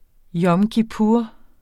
Udtale [ ˌjʌm kiˈpuɐ̯ ]